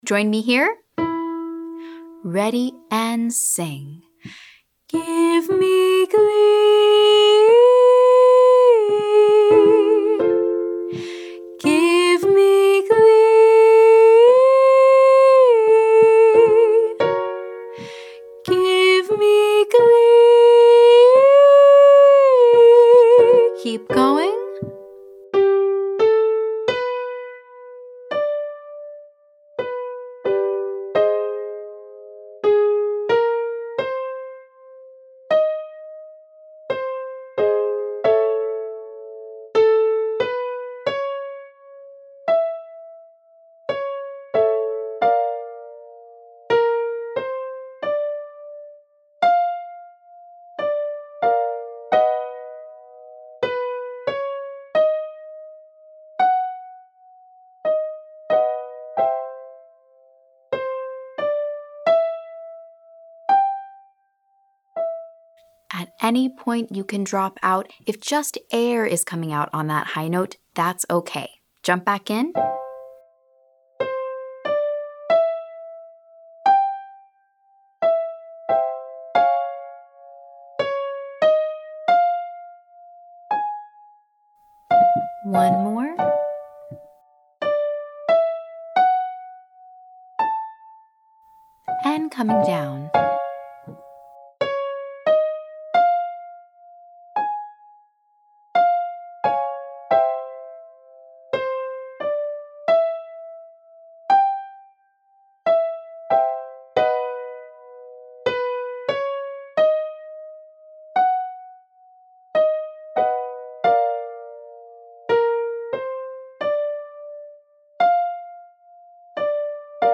Day 7: High Head Voice Extension
Exercise 1: Give me Glee 1 2 3-5-3